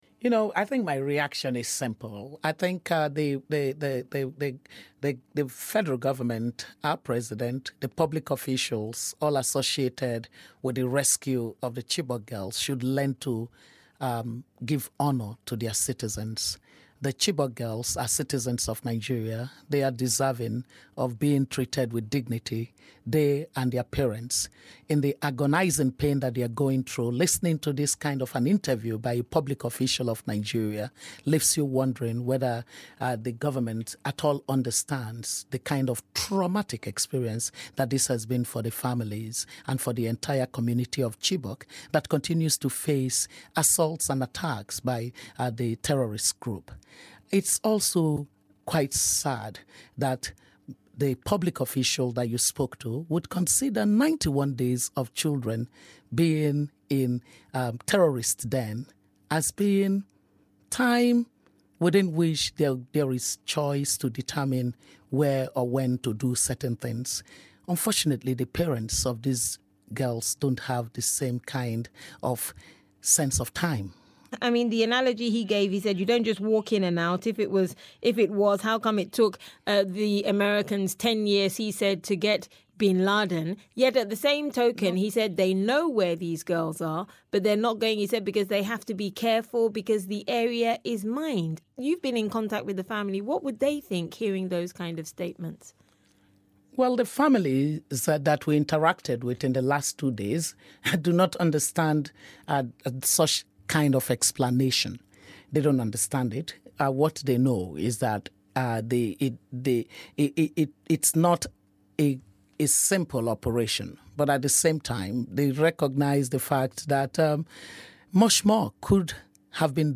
Interview with Bring Back Our Girls coordinator